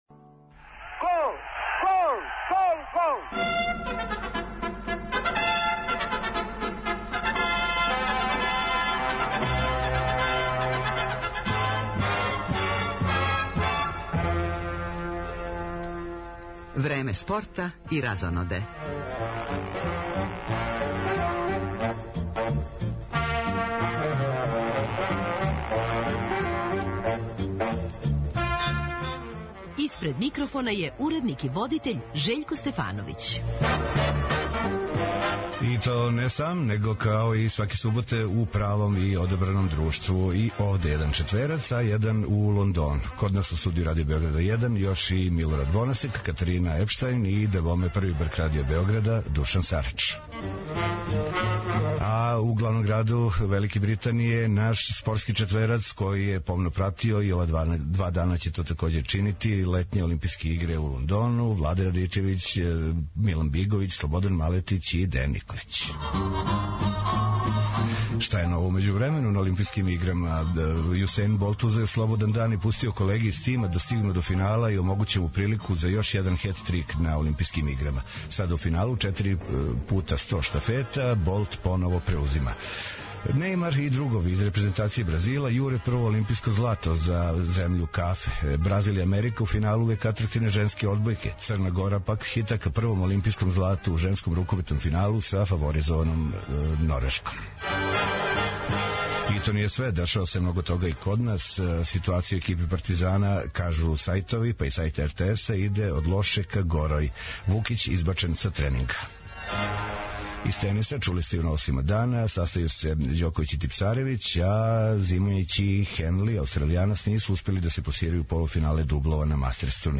Синоћ је утакмицом изме ђ у Рада и Радничког, почео нови шампионат Србије у фудбалу. Од 17.30 повремено ћемо чути рапорте с утакмица ОФК Београд - Доњи Срем и Јагодина - Хајдук, говорити и о кретању резултата на осталим утакмицама Супер и Прве лиге Србије.